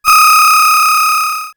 RetroGamesSoundFX
Ringing05.wav